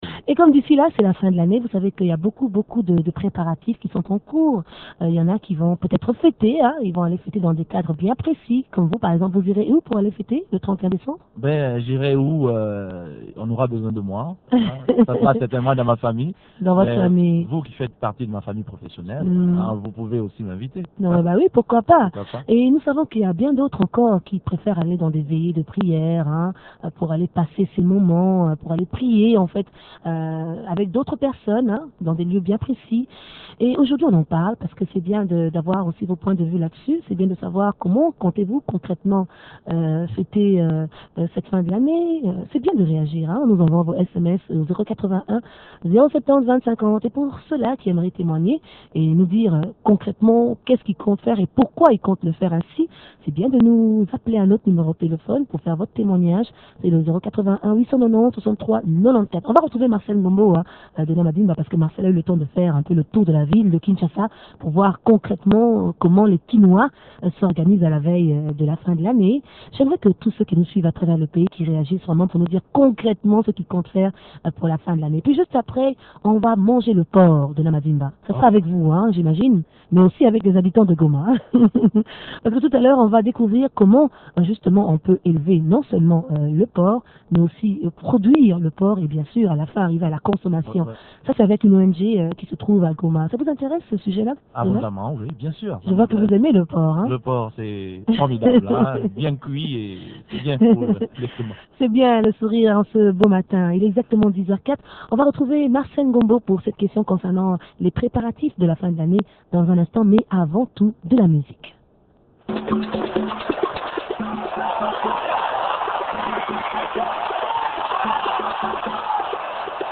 recoit un sociologue